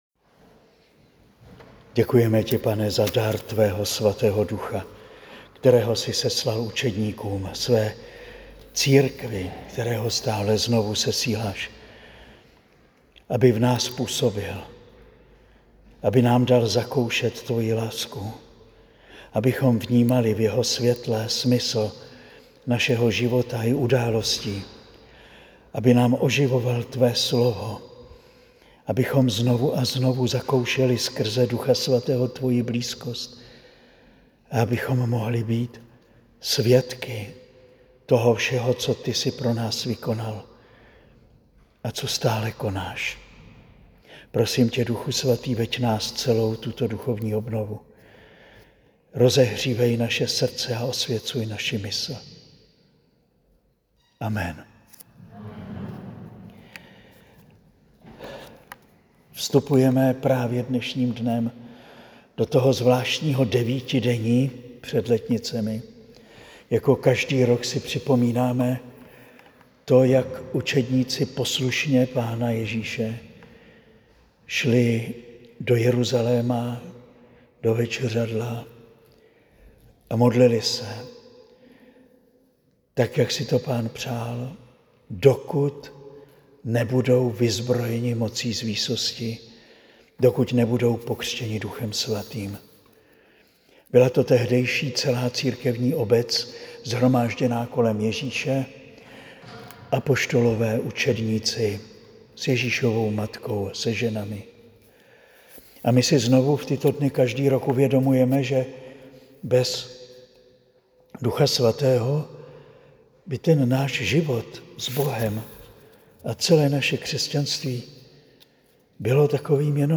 Nyní si můžete poslechnout první přednášku ze dvou.
Promluva zazněla na duchovní obnově v Klatovech v rámci přípravy na Slavnost Seslání Ducha Svatého.